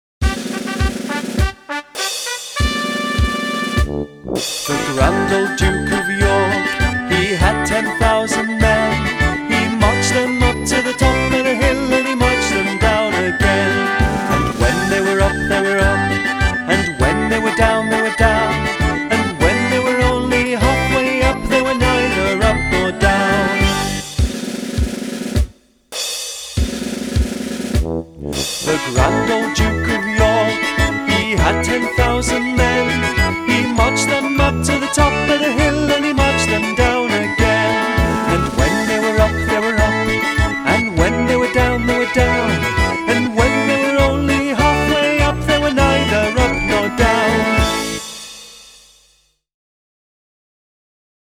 The Grand Old Duke of York - danse chantée (mp3)